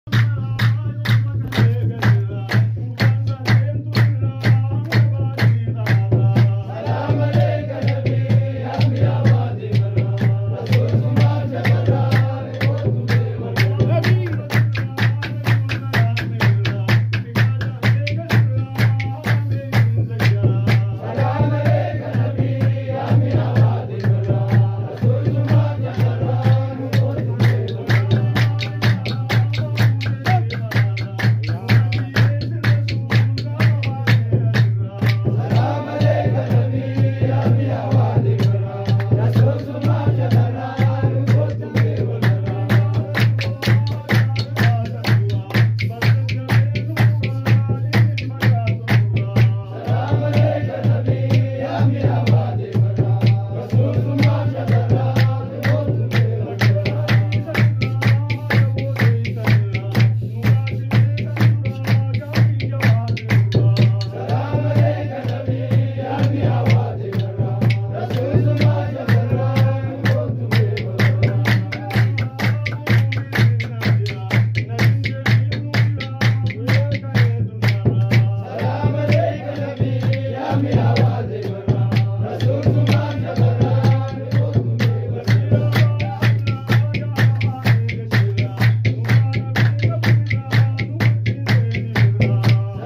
Oromo Menzuma
Sacred Harari music
Salawat Chanting